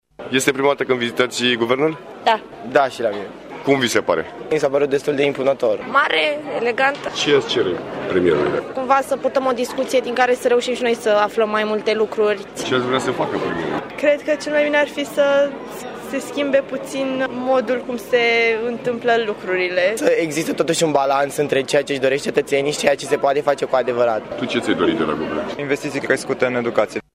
a stat de vorbă cu vizitatorii şi a aflat ce şi-ar dori de la Cabinetul condus de Dacian Cioloş: